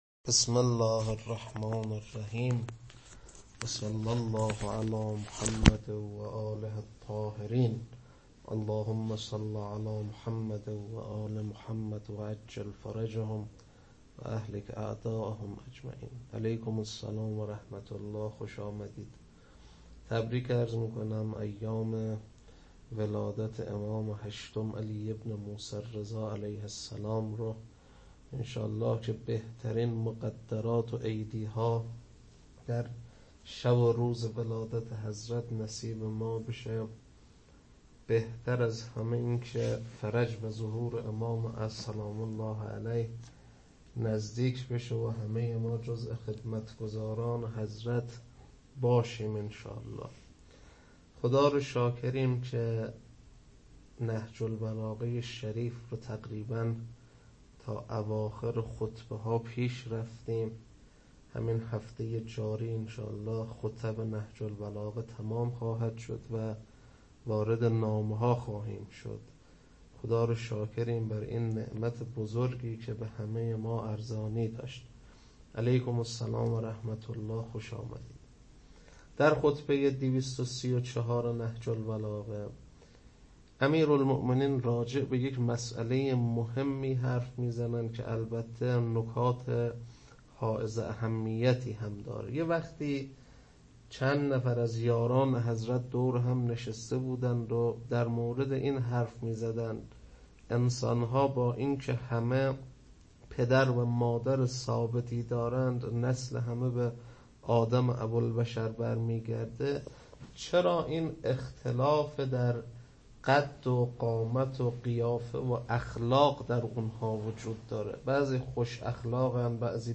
خطبه 234.mp3